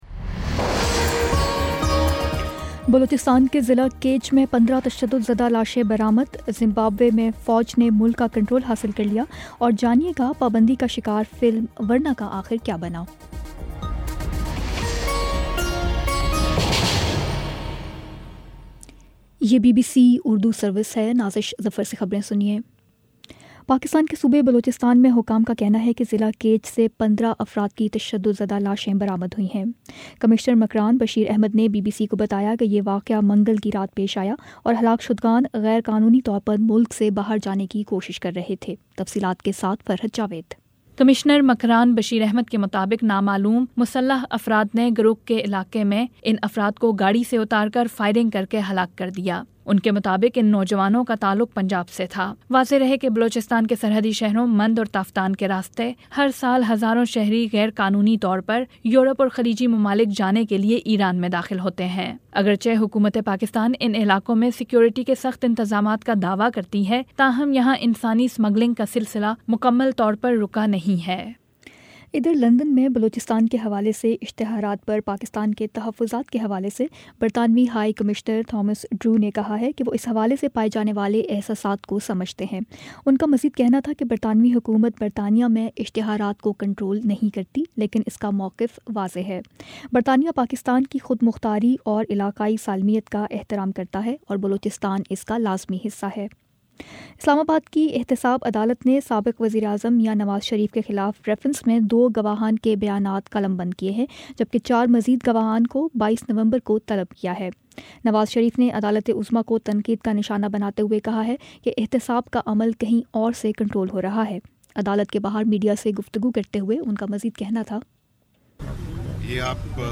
نومبر 15 : شام پانچ بجے کا نیوز بُلیٹن